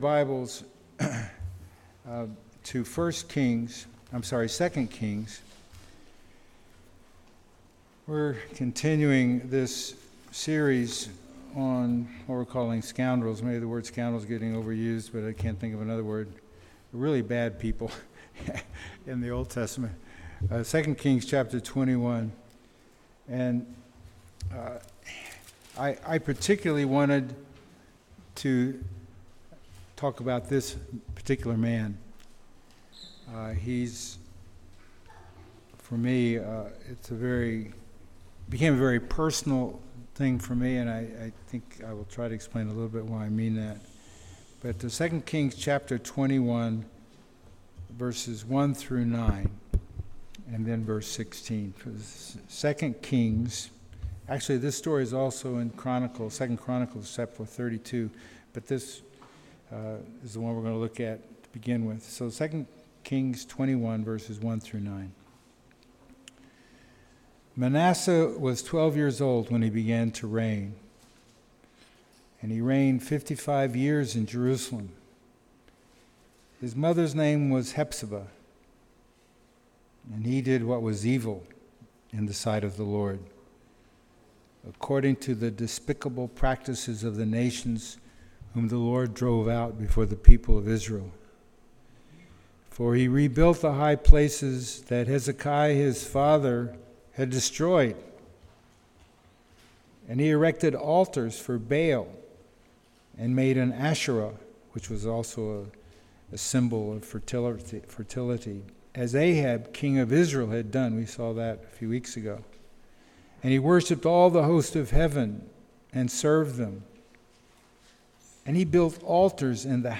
Sunday Morning | The Bronx Household of Faith